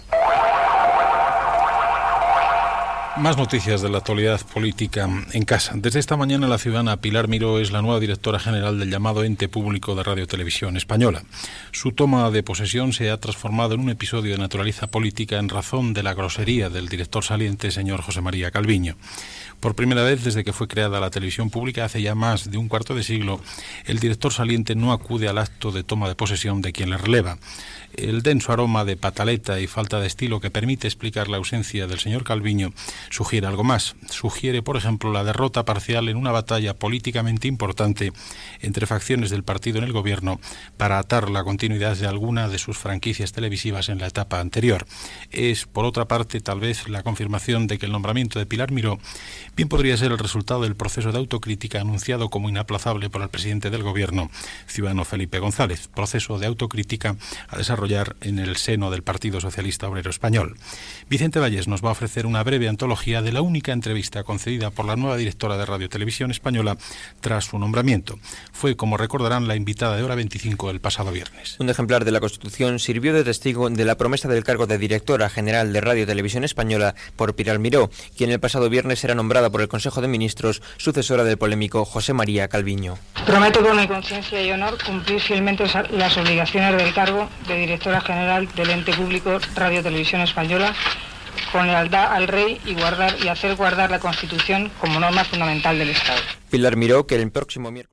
Identificació sonora del programa, Pilar Miró pren possessió del càrrec de Directora General de Radio Televisió Espanyola
Informatiu